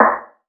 SNARE 075.wav